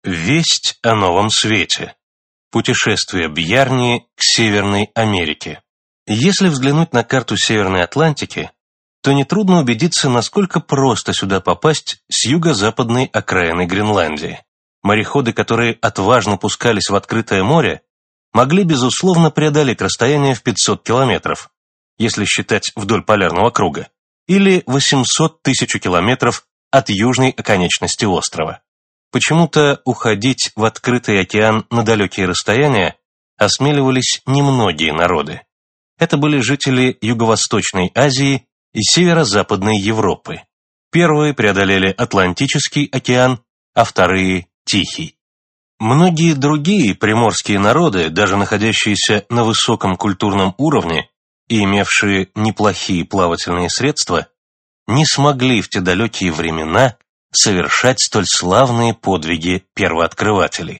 Аудиокнига Великие географические открытия | Библиотека аудиокниг